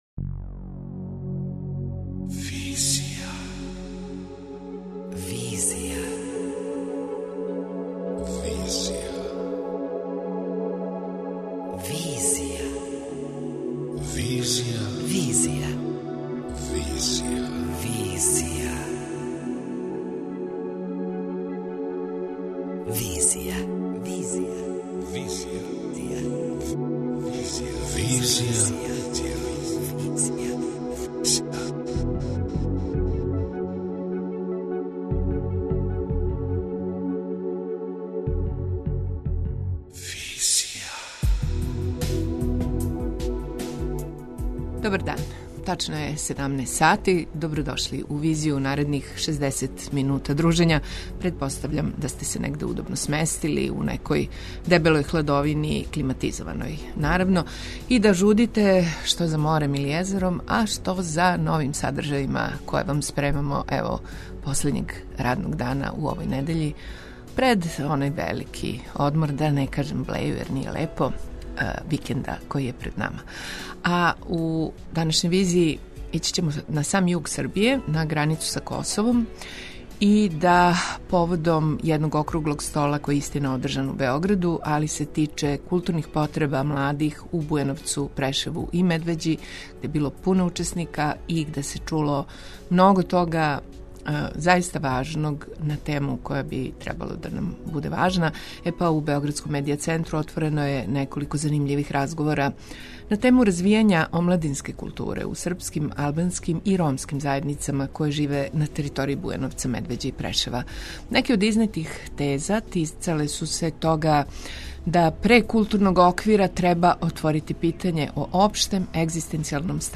У Београдском Медија центру отворено је неколико занимљивих разговора на тему развијања омладинске културе у српским, албанским и ромским заједницама које живе на територији Бујановца, Медвеђе и Прешева.